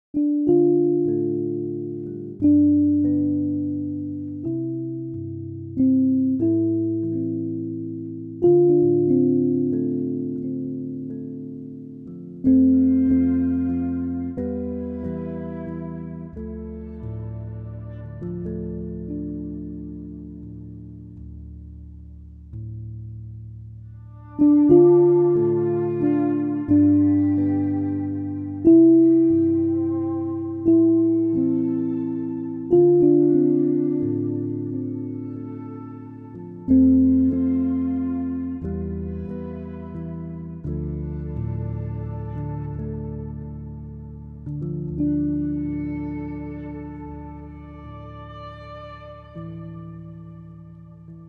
Minimal